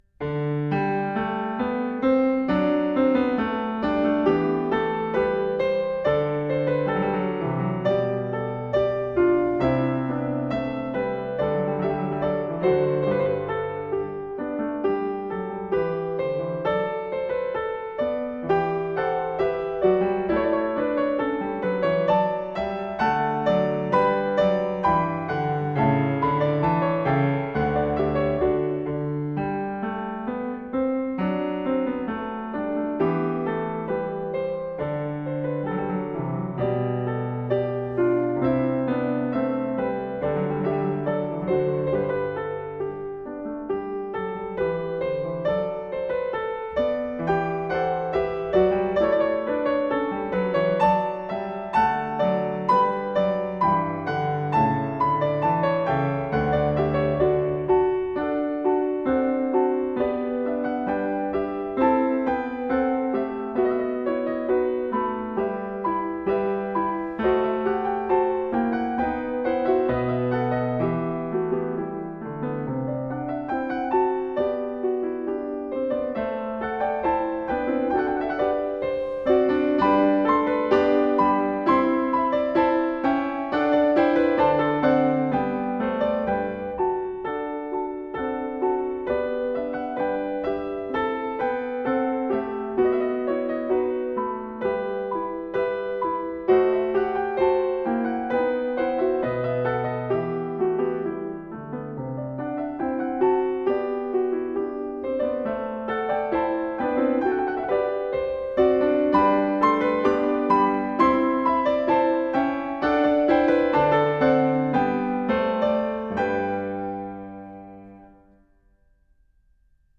Kimiko Ishizaka - J.S. Bach- -Open- Goldberg Variations, BWV 988 (Piano) - 30 Variatio 30 a 1 Clav.